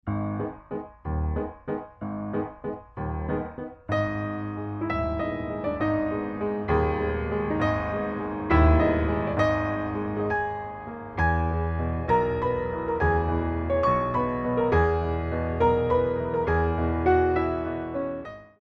3/4 - 32 with repeat
4 Count introduction included for all selections